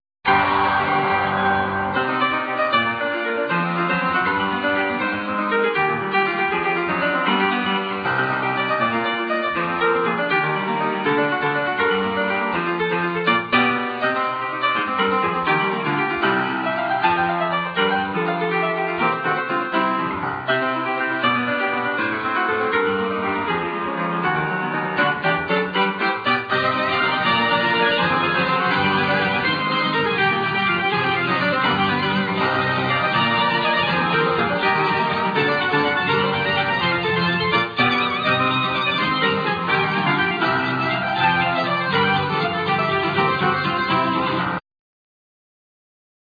Keyboard,Ac.guitar,Vocal,Percussions
Piano,Keyboard,Vocal
El.bass,Vocal,Percussions
Drums,Percussions
Soprano,Tenor sax